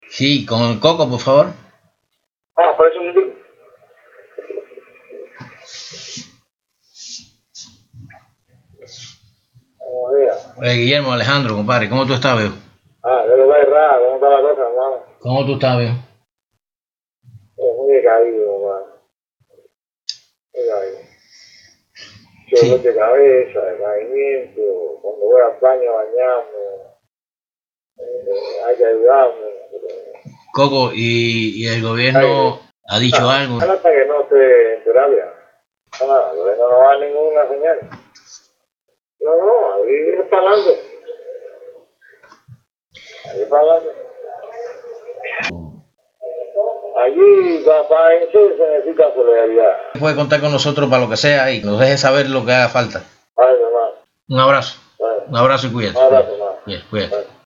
Conversación con Fariñas, antes de ser hospitalizado